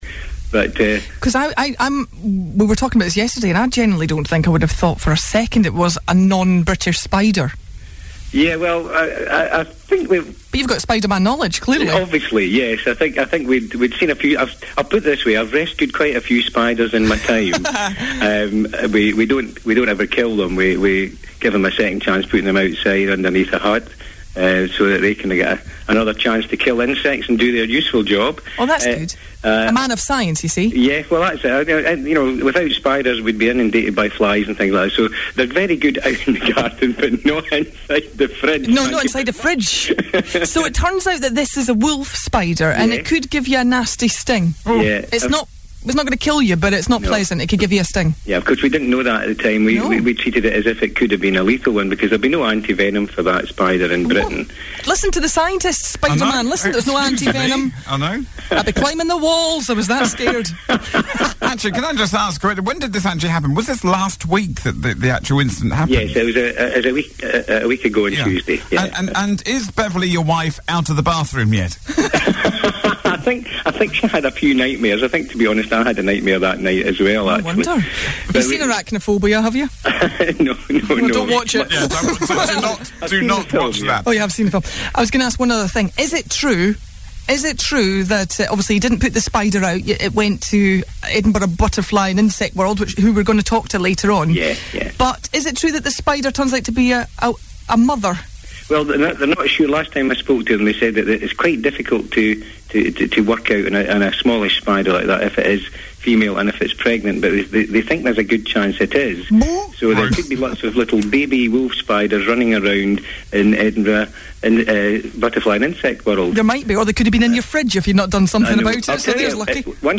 Part 2 of the Kingdom Breakfast Interview